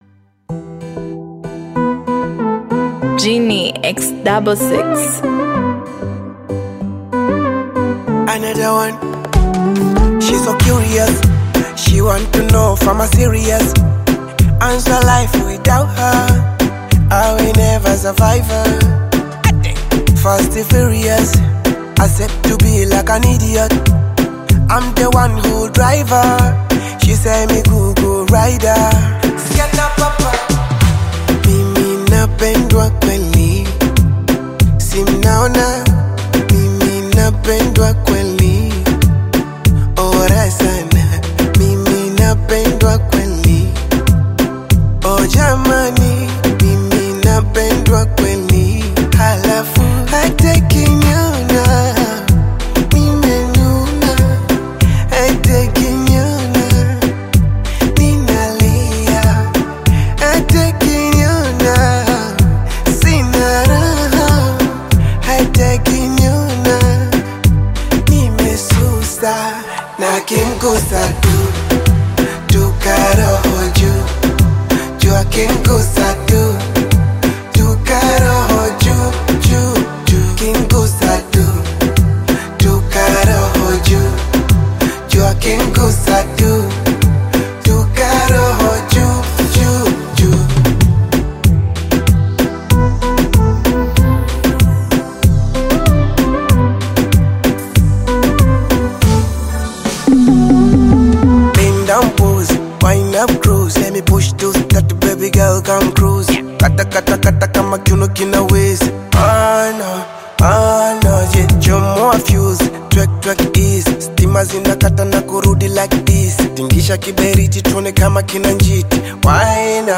heartfelt Afro-fusion single
Genre: Bongo Flava